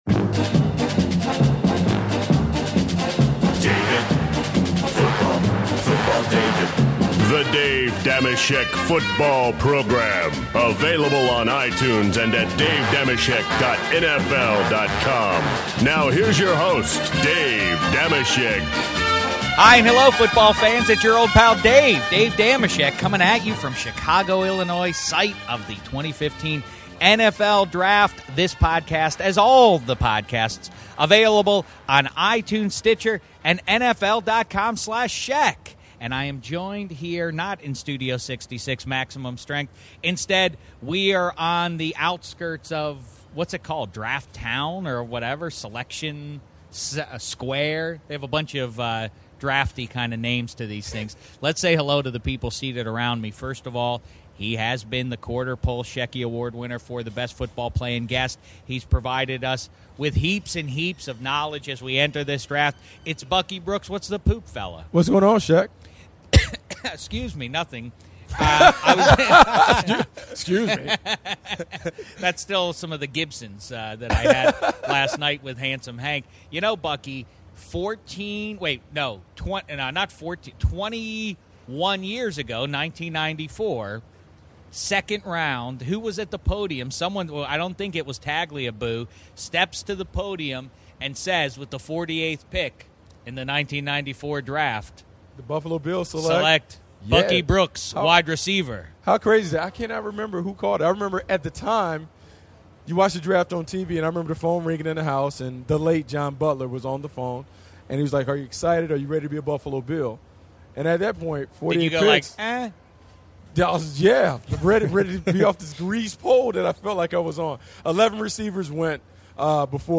Live from Chicago